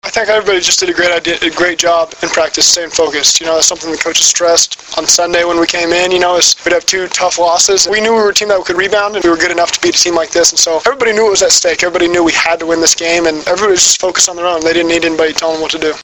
Below are links to audio segments of interviews done with Husker players and head coach Bill Callahan after Nebraska's 34-20 win over Missouri.
Quarterback Zac Taylor